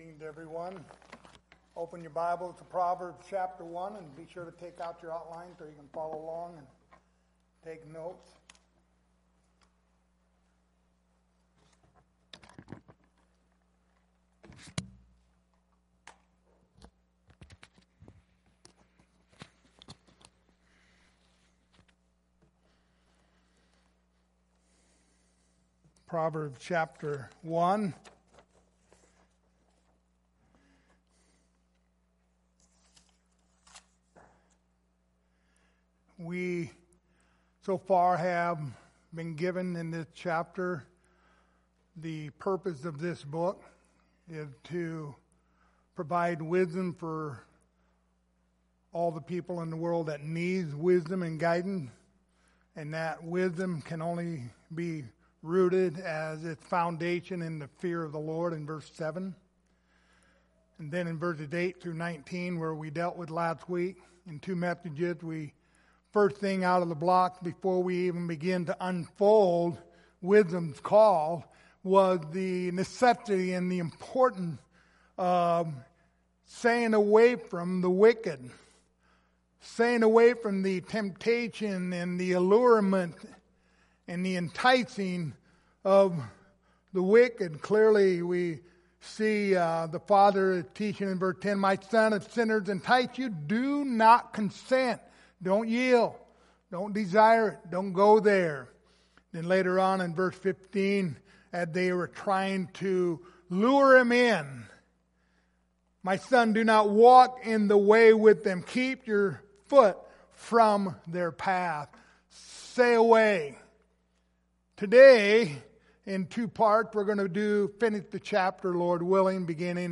The Book of Proverbs Passage: Proverbs 1:20-27 Service Type: Sunday Morning Topics